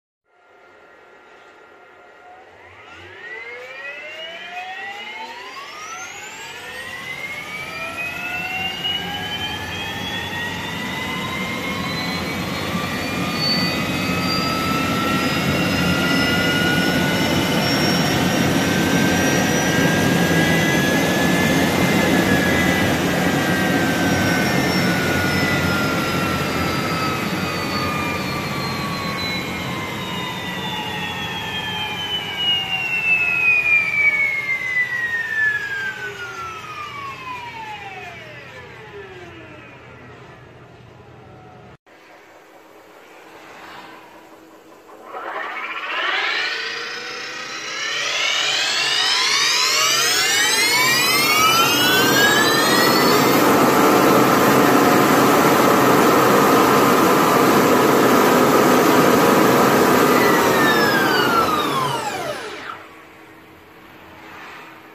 你可能和我一样好奇，高转速电机的声音是什么样的，董车会找了一段特斯拉电机引擎高速运转时的录音，可以感受一下。